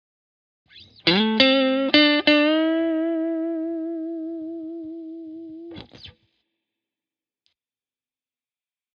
Apply, for instance, a slide to one of the notes or you could use a hammer-on or pull-off technique.
Listen here to these same notes but with applied techniques